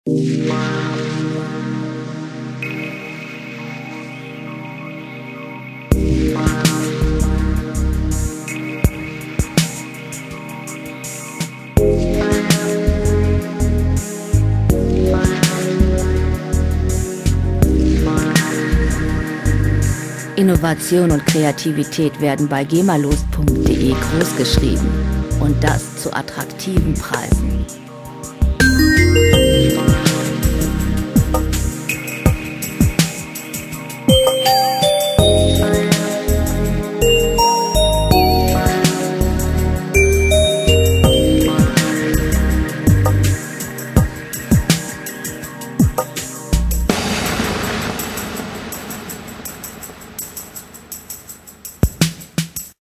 Elektronische Musik - Weltraum - Space Music
Musikstil: Space Music
Tempo: 86 bpm
Tonart: C-Moll
Charakter: schwebend, hypnotisch
Instrumentierung: Synthesizer, Chor, Drums, Bass, Effekte